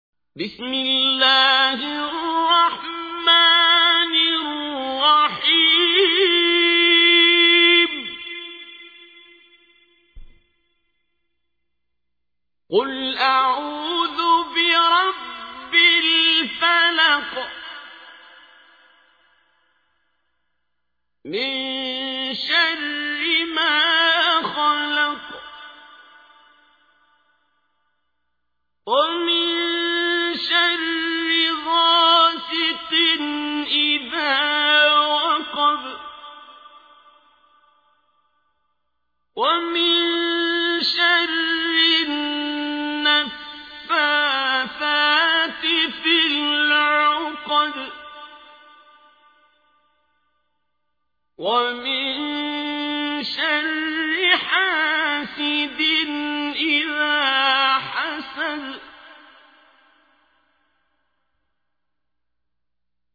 تحميل : 113. سورة الفلق / القارئ عبد الباسط عبد الصمد / القرآن الكريم / موقع يا حسين